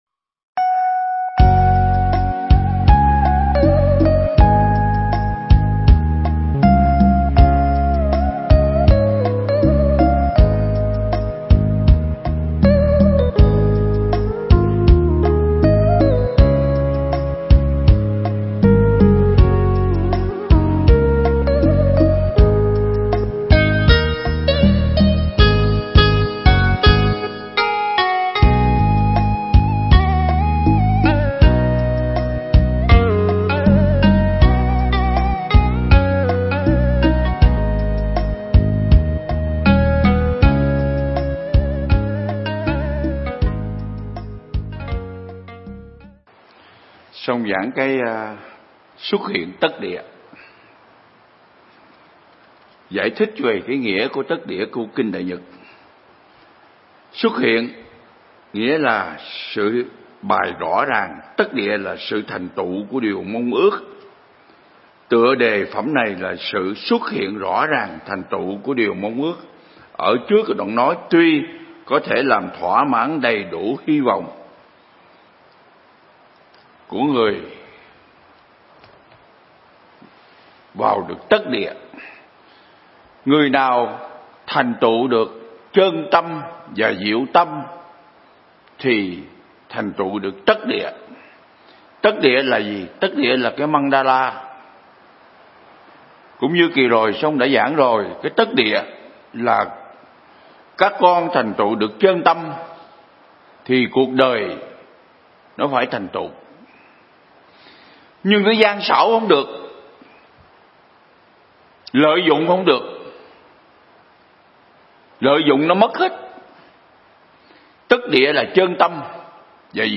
Thuyết Pháp
Pháp Thoại
giảng tại Viện Nghiên Cứu Và Ứng Dụng Buddha Yoga Việt Nam (TP Đà Lạt)